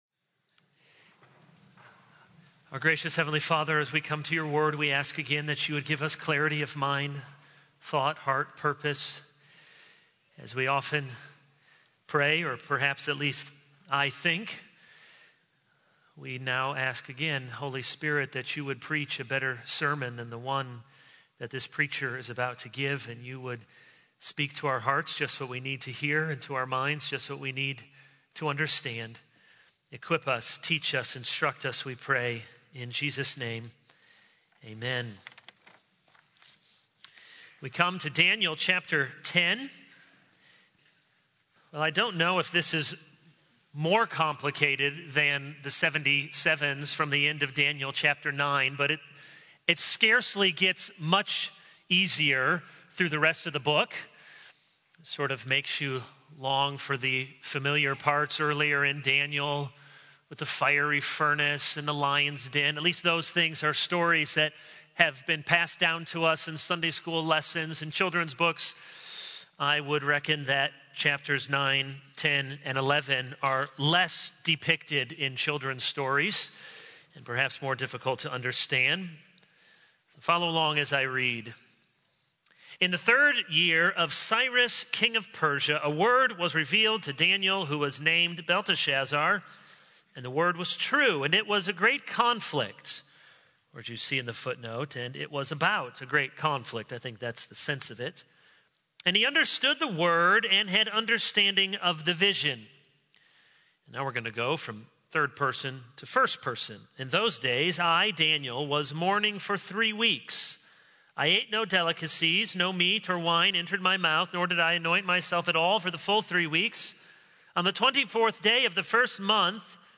All Sermons A Study in Wise Words: Wealth and Poverty 0:00 / Download Copied!
Pastor Kevin DeYoung